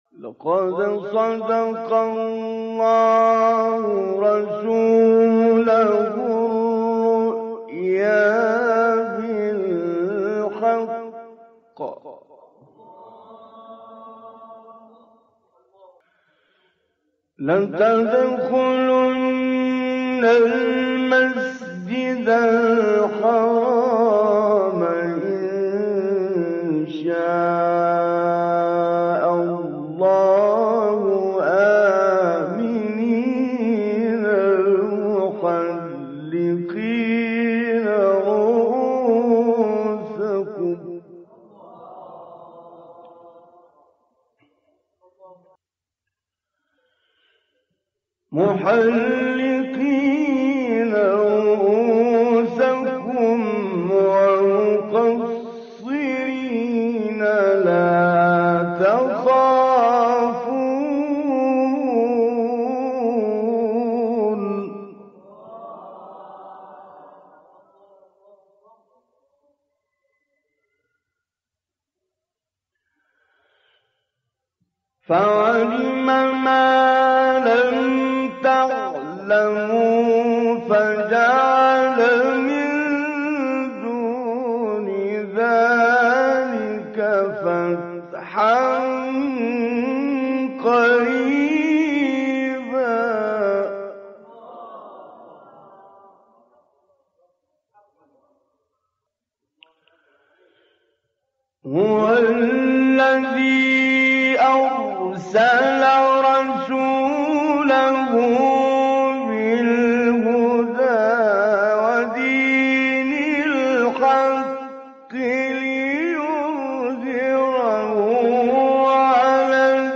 تلاوت سوره فتح استاد طنطاوی | نغمات قرآن | دانلود تلاوت قرآن